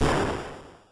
Voltorb Flip Explosion.mp3